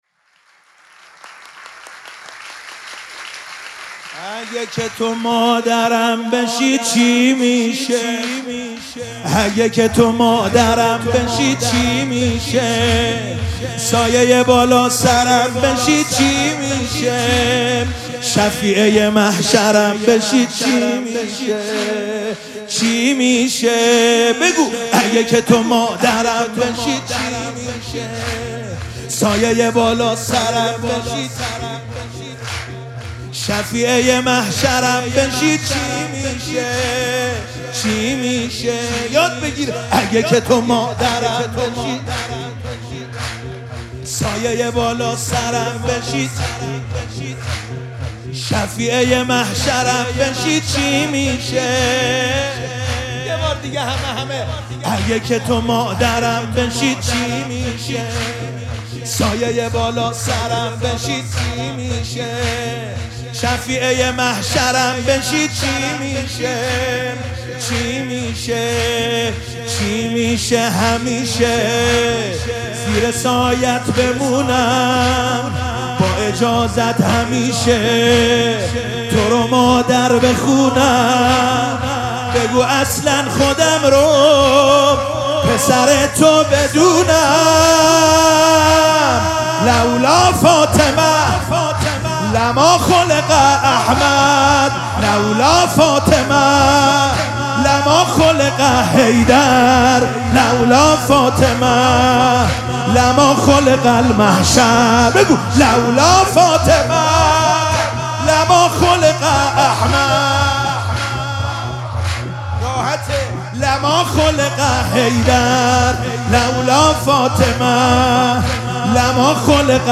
مراسم جشن ولادت حضرت زهرا سلام الله علیها
سرود
مداح